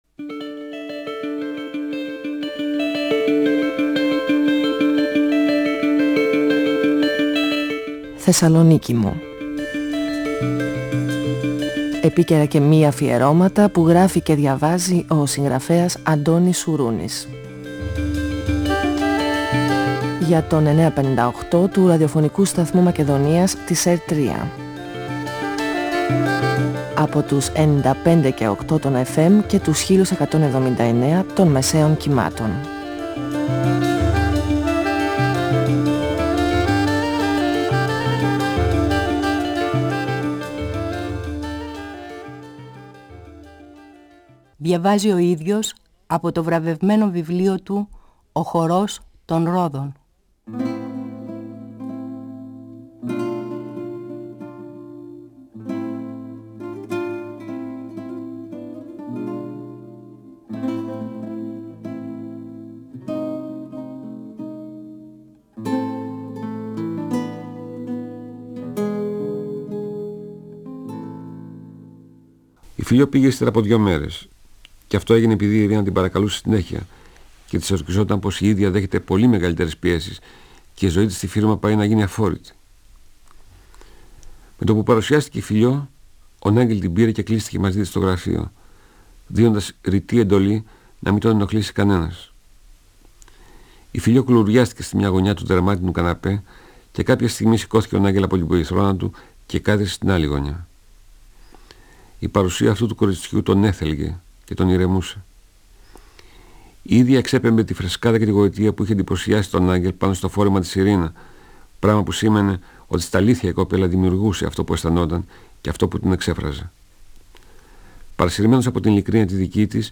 Ο συγγραφέας Αντώνης Σουρούνης (1942-2016) διαβάζει από το βιβλίο του «Ο χορός των ρόδων», εκδ. Καστανιώτη, 1994. Η Φιλιώ, η σύντροφος τού Τάκη, συναντά τον διάσημο μόδιστρο Γιαν Νάγκελ και αλλάζει η ζωή της.